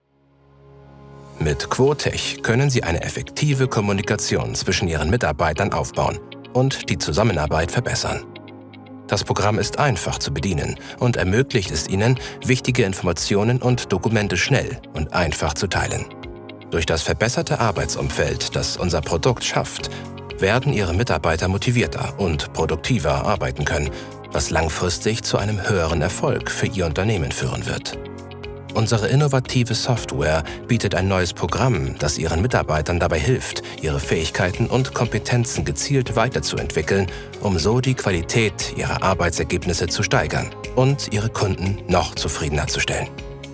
Commercial, Deep, Natural, Cool, Warm
E-learning